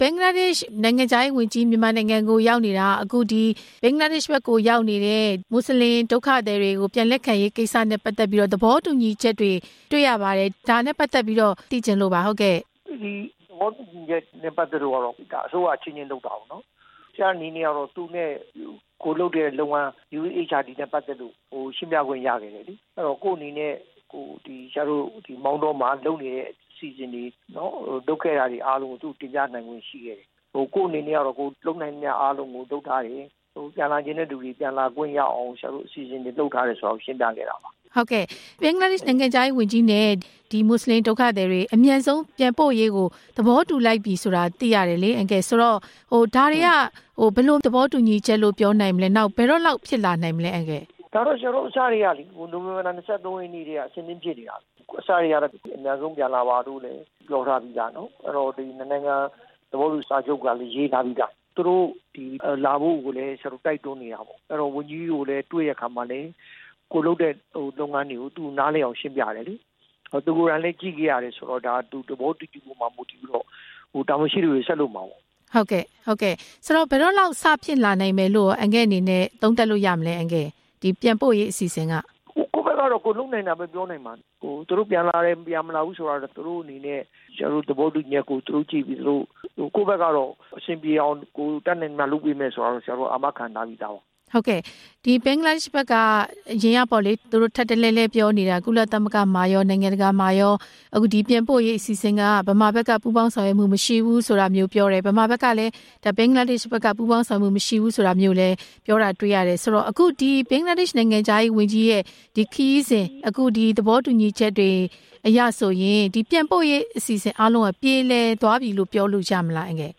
ဒေါက်တာအောင်ထွန်းသက်နှင့် ဆက်သွယ်မေးမြန်းချက်